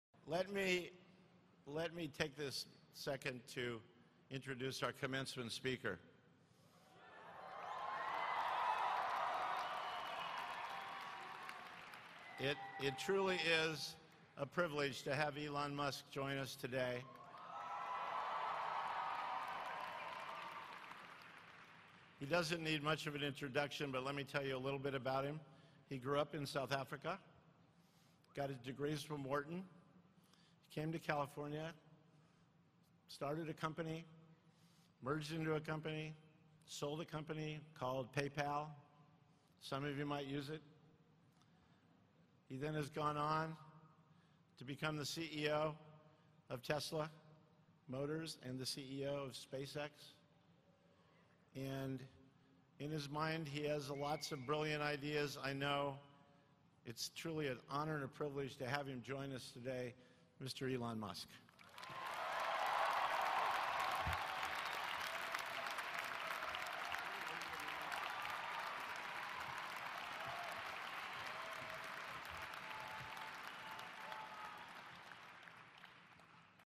公众人物毕业演讲第41期:特斯拉教父伊隆马斯克南加大(1) 听力文件下载—在线英语听力室